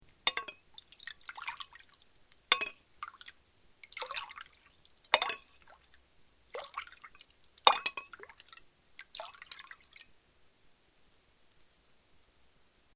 Sirviendo sopa
cazo
Sonidos: Acciones humanas
Sonidos: Alimentación